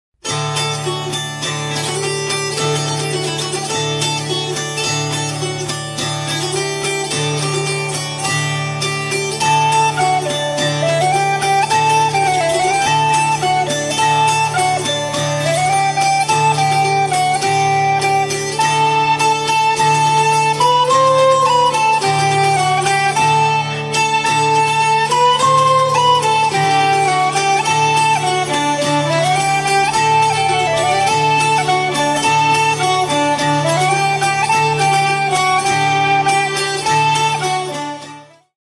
Le Rond des Épinettes
épinette des Vosges
Violon
flageolet, accordéon diatonique